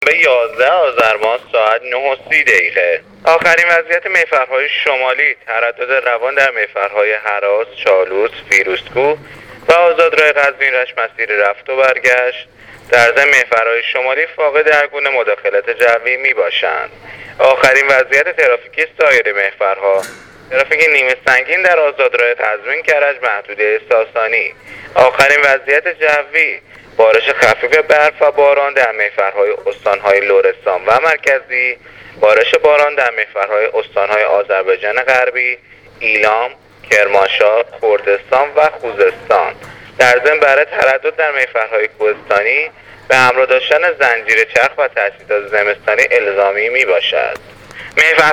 گزارش رادیو اینترنتی از آخرین وضعیت ترافیکی جاده‌ها تا ساعت ۹:۳۰ یازدهم آذر ۱۳۹۸: